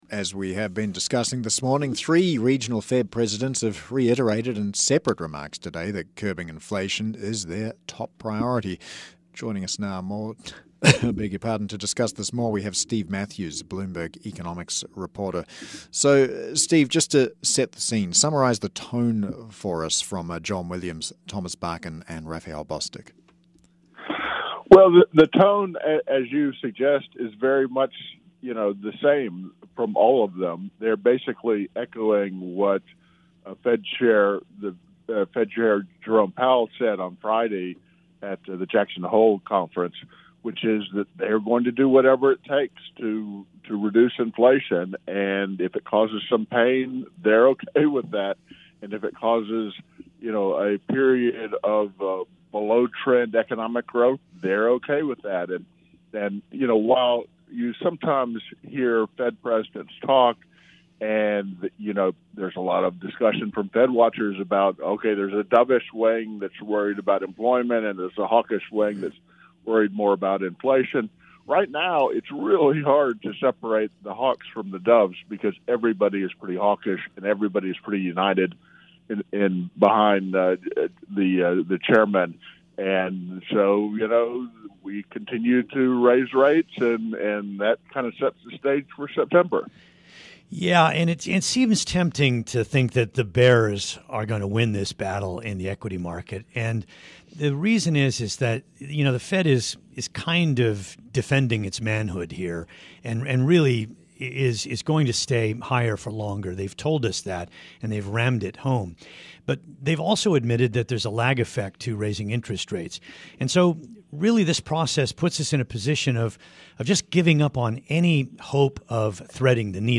on Bloomberg Radio